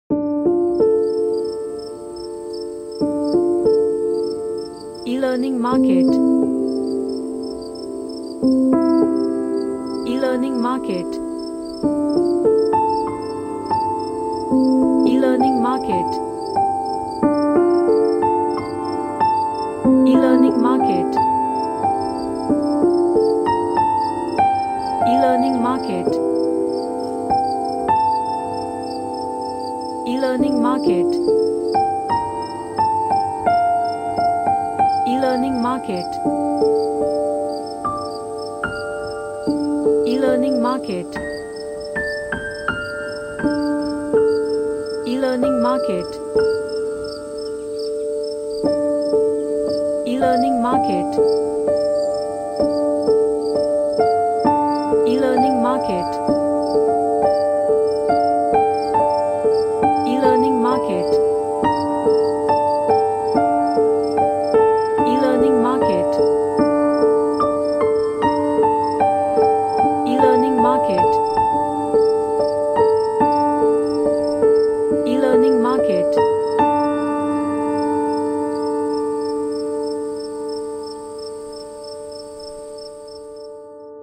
An emotional sounding piano track
Emotional